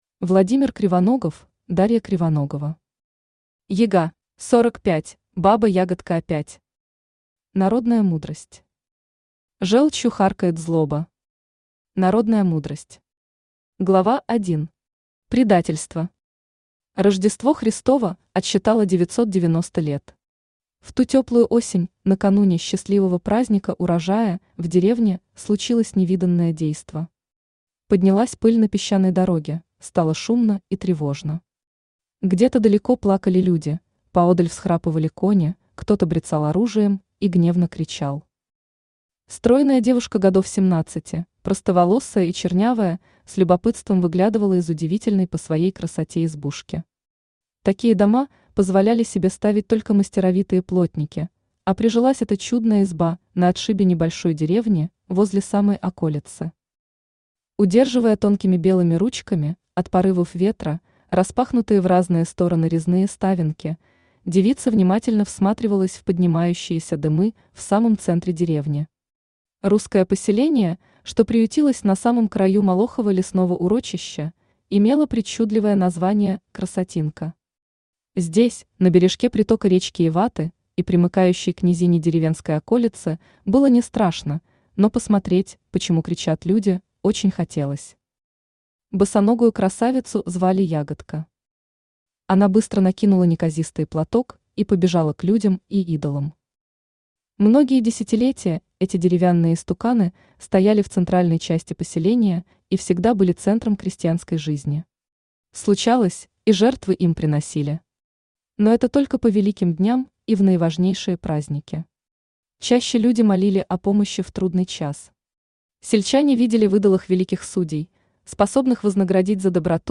Aудиокнига Яга Автор Владимир Кривоногов Читает аудиокнигу Авточтец ЛитРес.